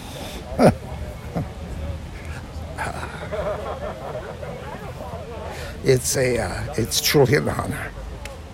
When asked how he felt seeing the plaque bearing his face and commemorating his work, a choked-up Boyce summed it up in very few words.